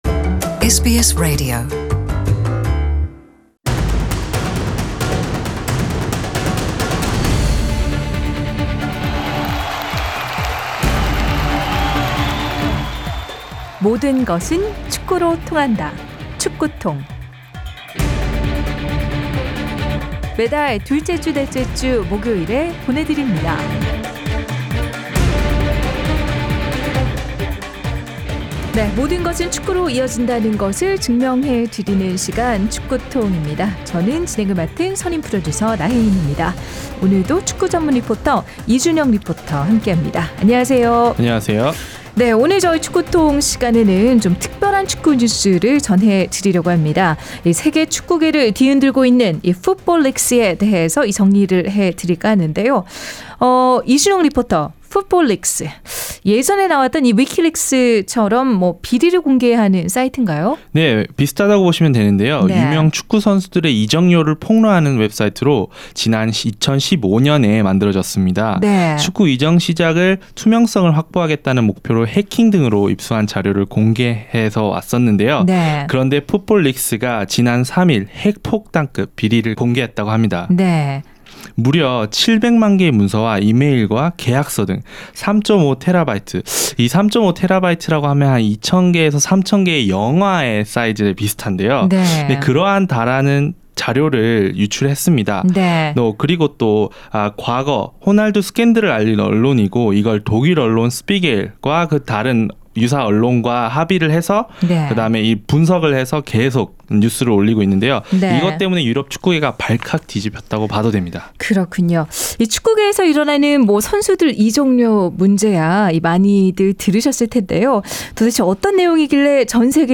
Soccer TONG is a sports segment that proves how everything leads to soccer.